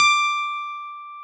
Index of /90_sSampleCDs/Keyboards of The 60's and 70's - CD2/PNO_E.Grand/PNO_E.Grand